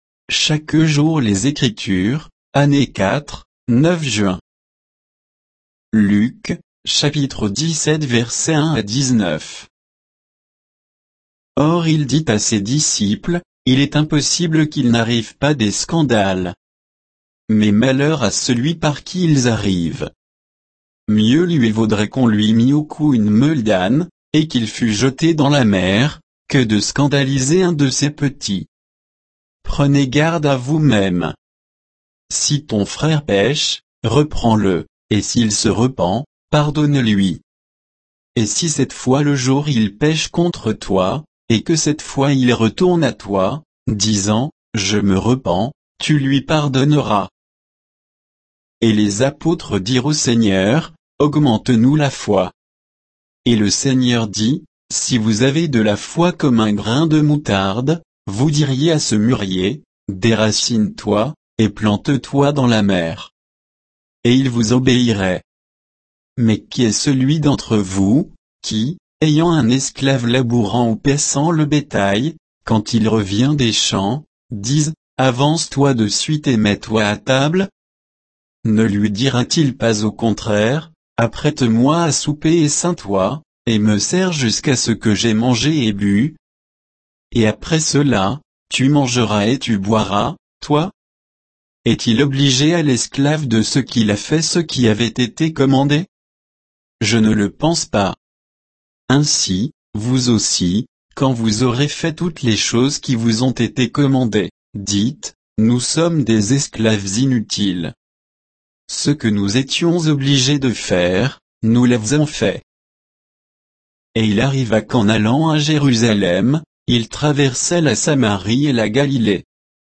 Méditation quoditienne de Chaque jour les Écritures sur Luc 17, 1 à 19